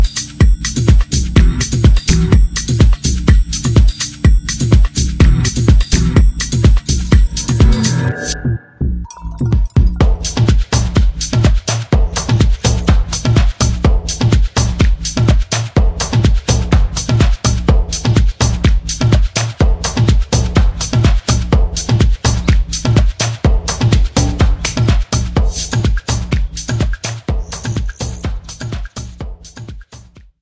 REMIX TRACKS